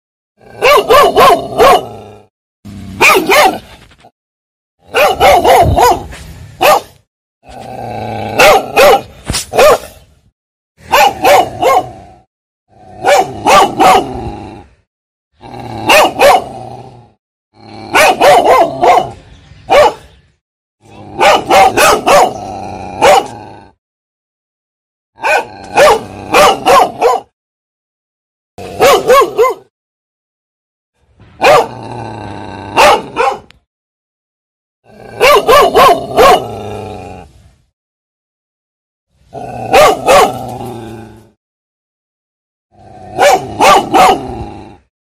Tiếng Chó Sủa Dữ Dội MP3